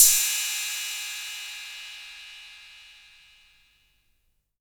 808CY_3_Tape.wav